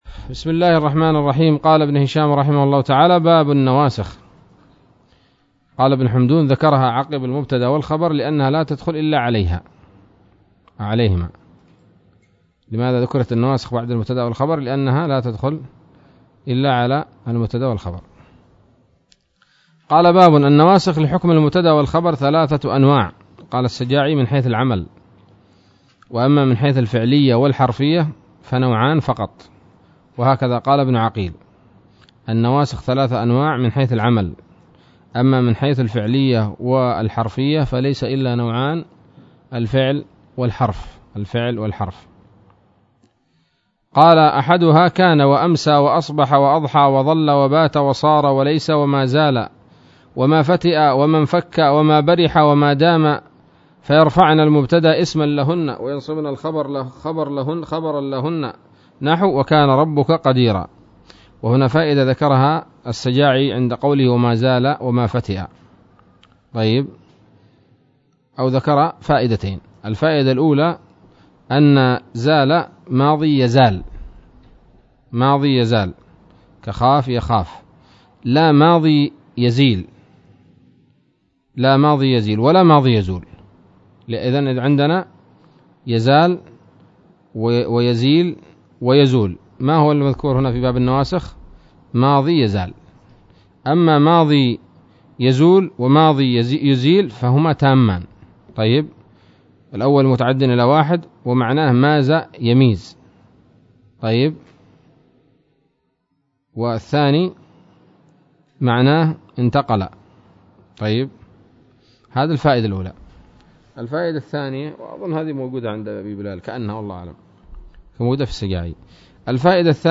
الدرس السادس والخمسون من شرح قطر الندى وبل الصدى